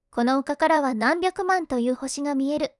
voicevox-voice-corpus / ita-corpus /もち子さん_ノーマル /EMOTION100_044.wav